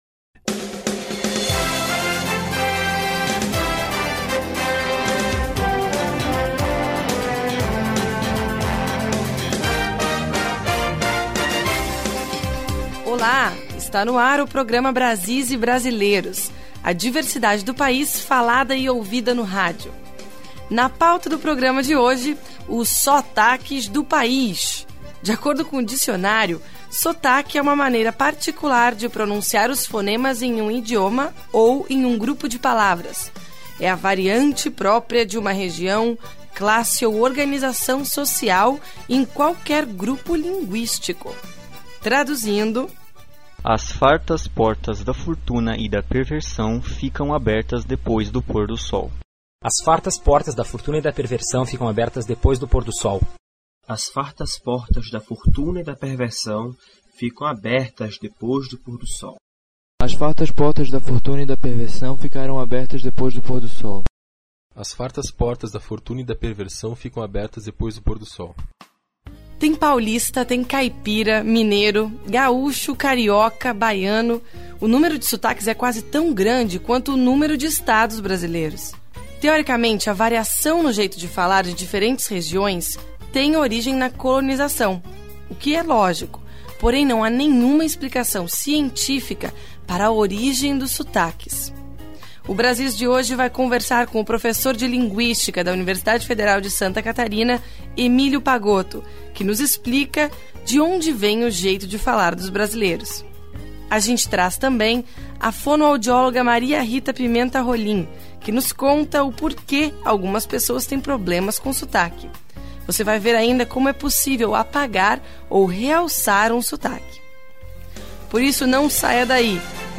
Documentário